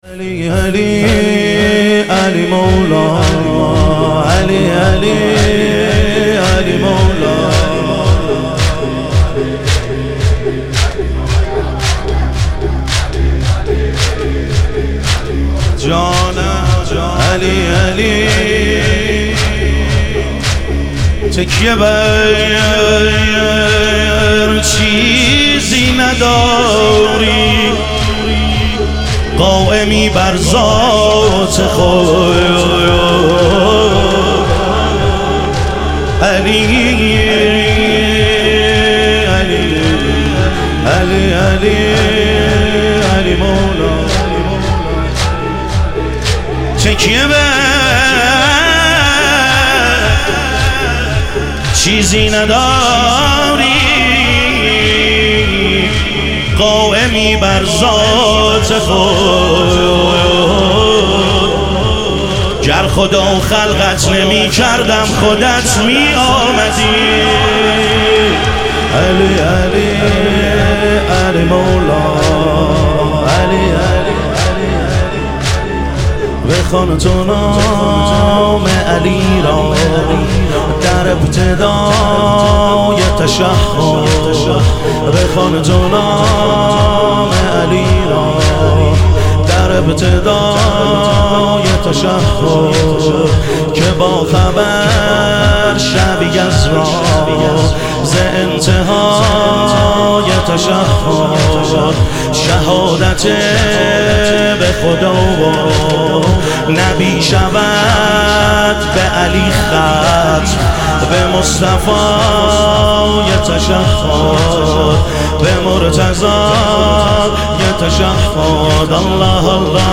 شب شهادت امام صادق علیه السلام